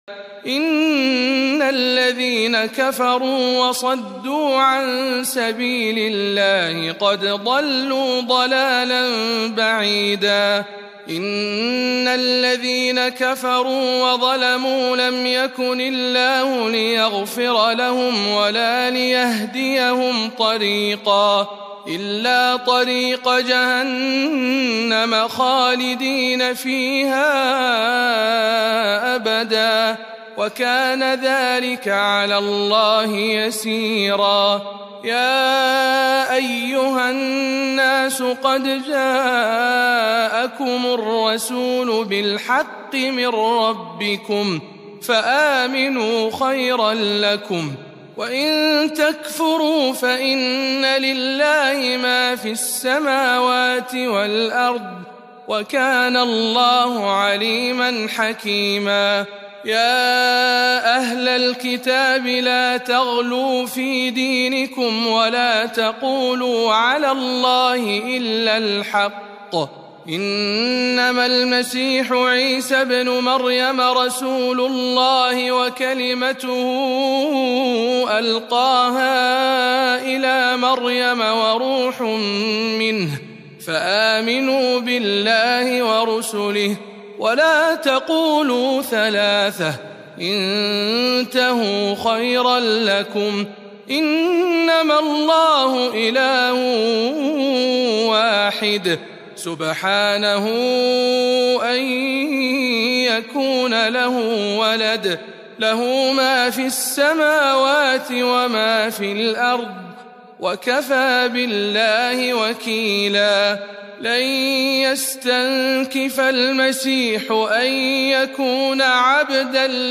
تلاوة مميزة من سورة النساء